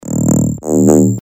громкие
мощные басы
вибрация